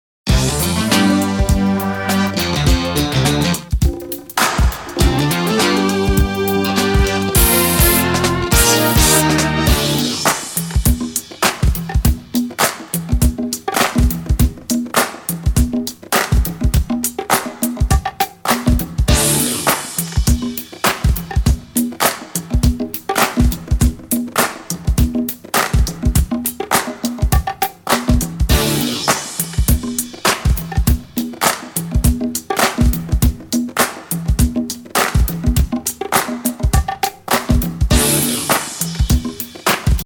VOCAL EDIT VERSION